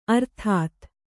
♪ arthāt